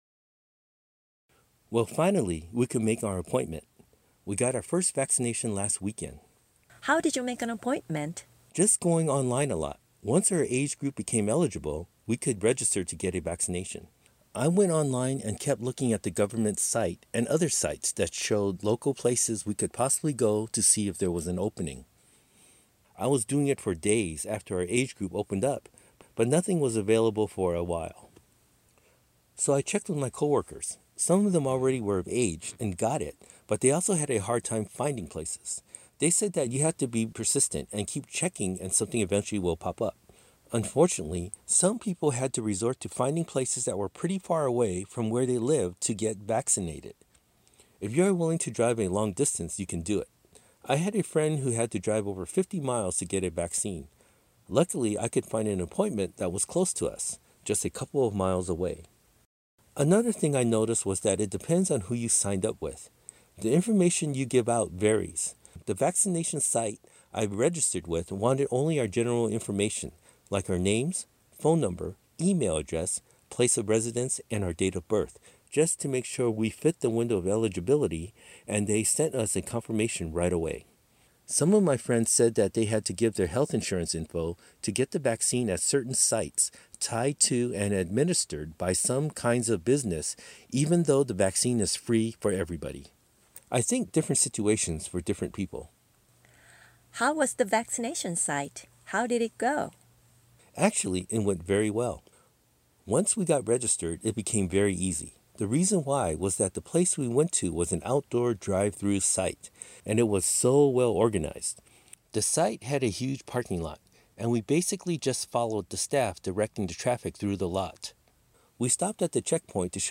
アメリカ人の夫にインタビュー【ワクチン体験：予約から接種まで】ネイティブの発音 で聴ける オーディオ付き
ここではアメリカ人が、友人や同僚と普通に話している時の「自然な日常会話の表現」を聴くことができます